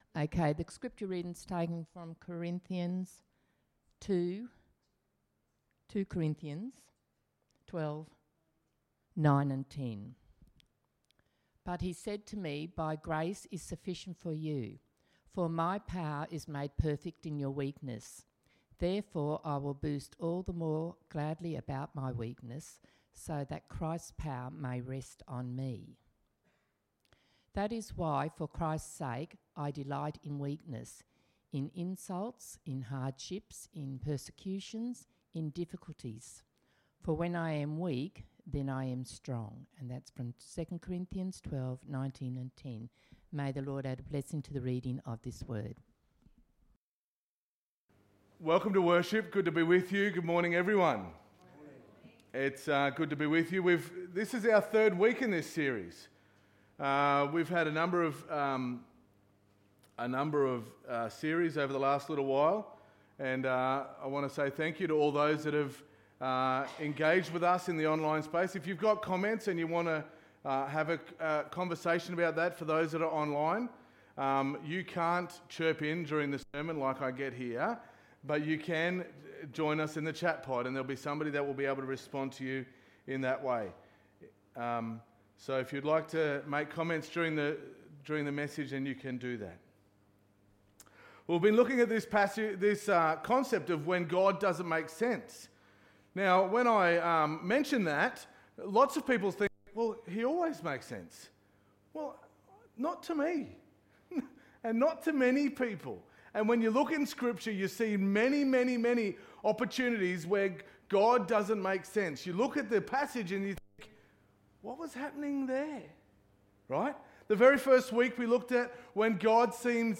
Sermon 19.97.2020